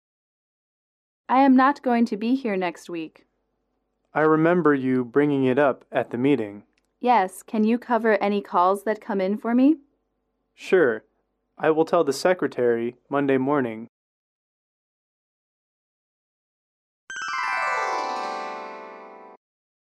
英语口语情景短对话09-2：交托工作